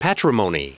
Prononciation du mot patrimony en anglais (fichier audio)
Prononciation du mot : patrimony